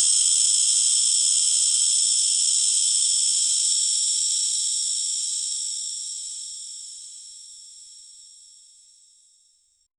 snake-hiss-jwcwx26c.wav